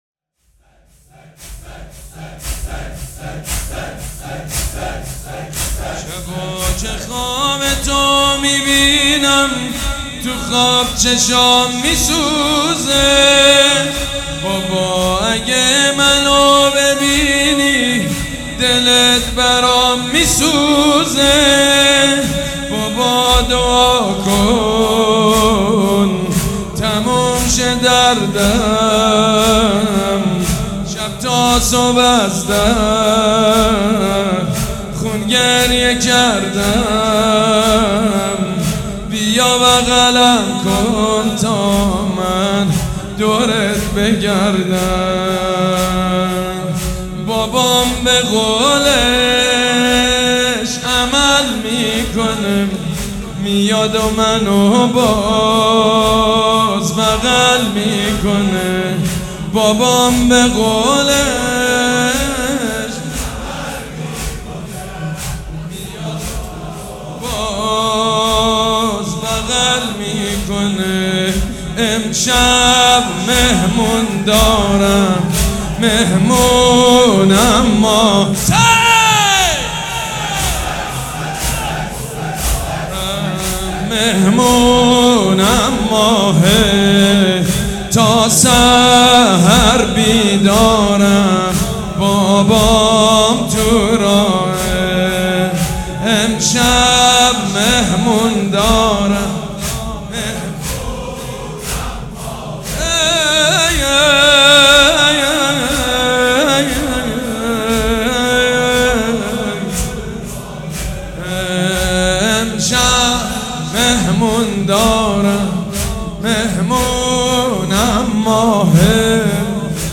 سبک اثــر زمینه
مداح حاج سید مجید بنی فاطمه
مراسم عزاداری شب سوم